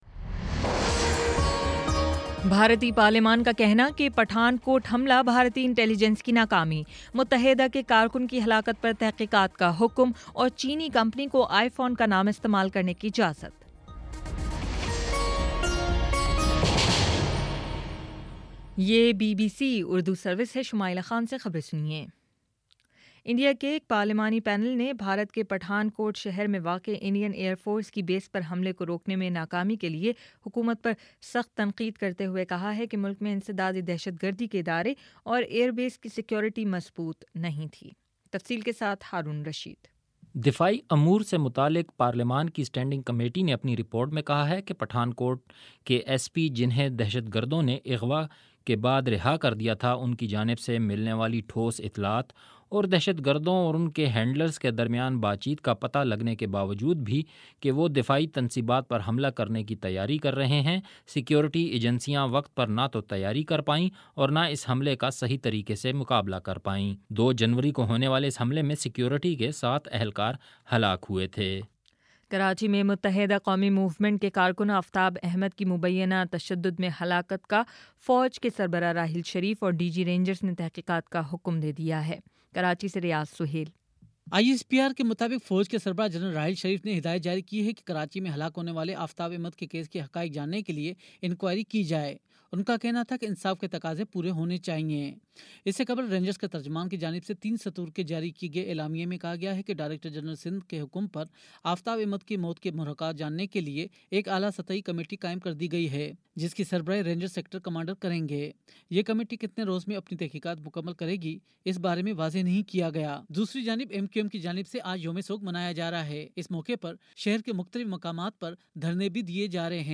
مئی 04 : شام چھ بجے کا نیوز بُلیٹن